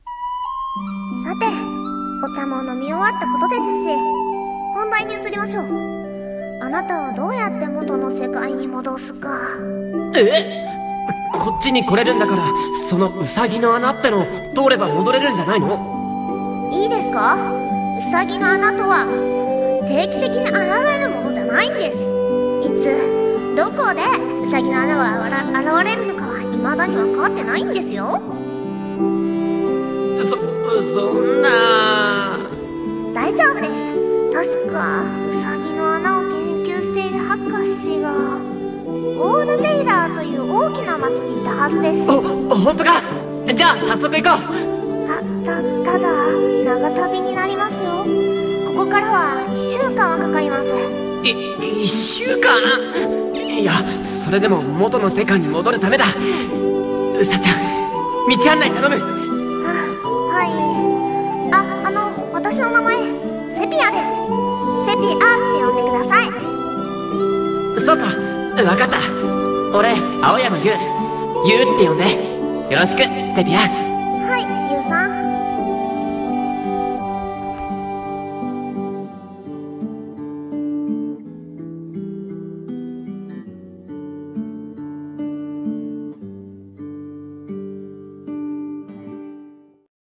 声劇台本｢異世界への漂流者２｣